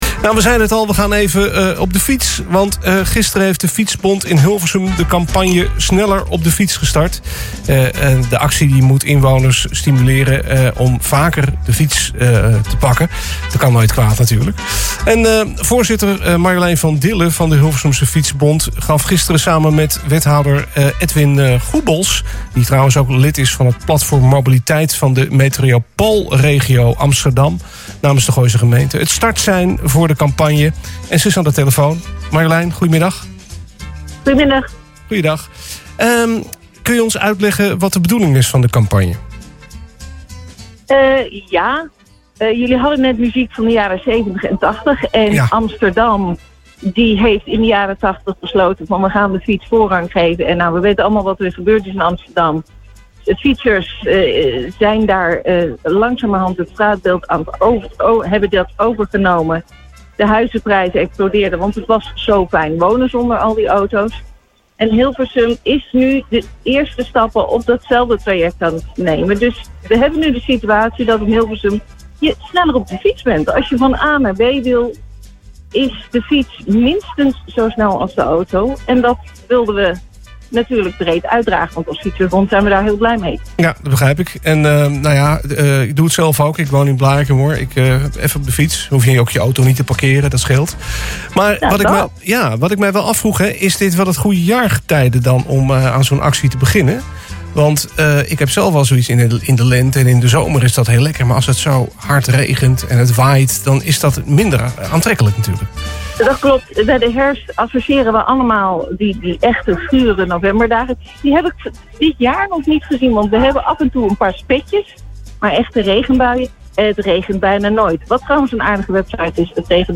het startsein voor de campagne en ze is aan de telefoon in de Lunchclub.